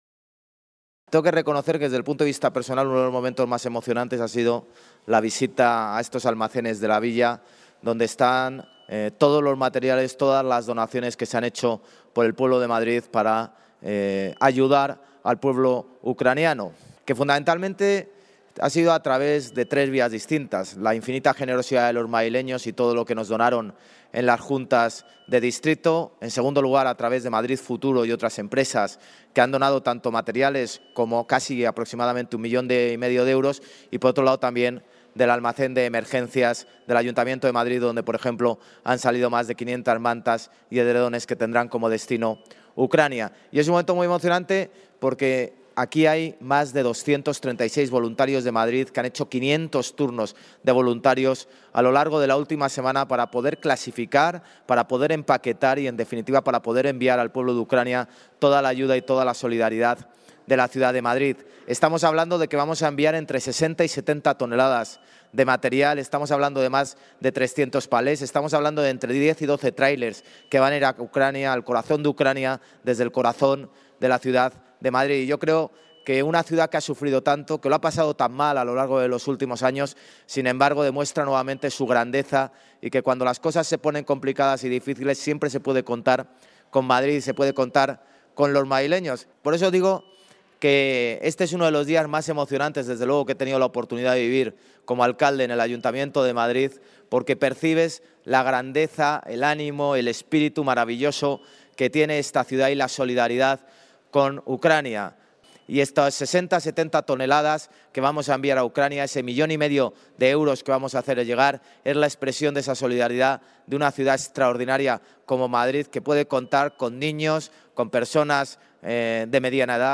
JLMartinezAlmeida-VisitaPuntoRecepcionAyudaUcrania-16-03.mp3